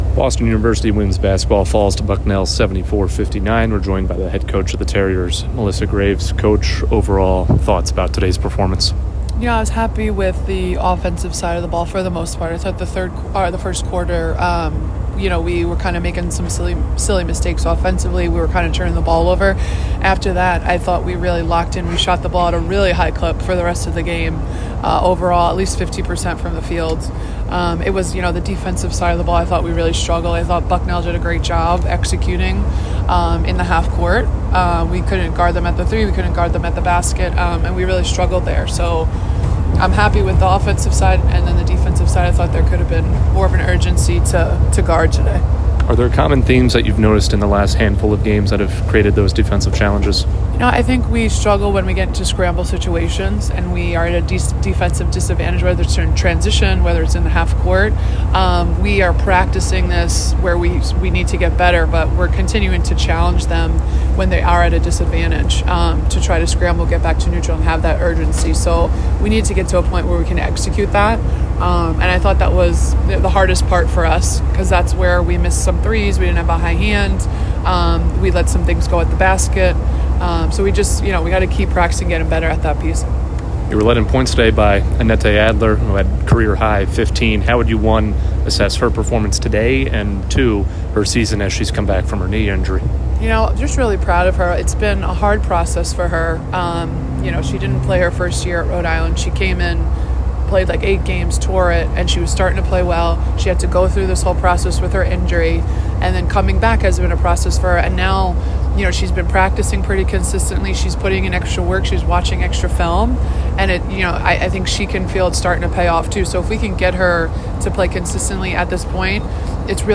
WBB_Bucknell_1_Postgame.mp3